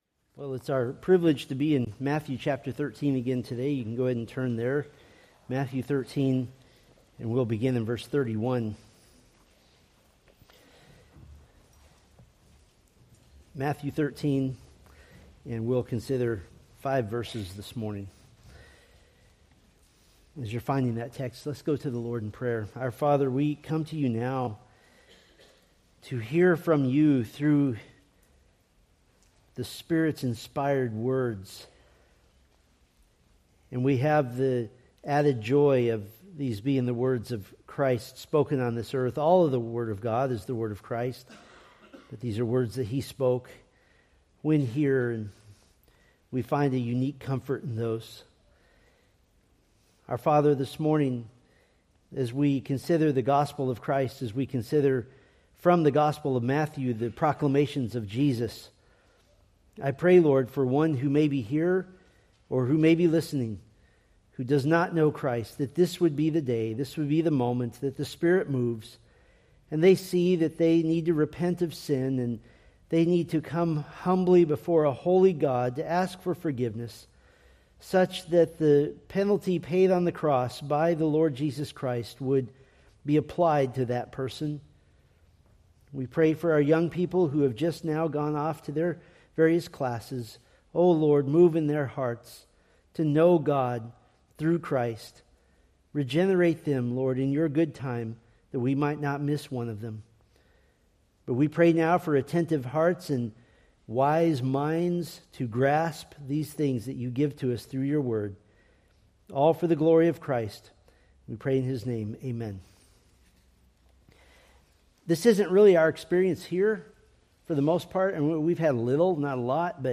Preached February 22, 2026 from Matthew 13:31-35